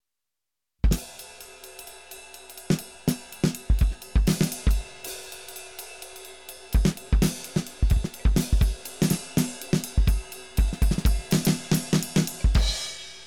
Det är bättre "bett" i cymbalen på den olimiterade, den limiterade låter inte lika "kort" i attacken utan mer utbredd i tid. Mer pshhh än ktjing.
Trummorna kan vara processade, jag tog bara en snutt som jag hade tillgång till, jag skulle gissa på tape-kompression.
Trummor_Limiterad_Peak-11dB_EBU_R128.wav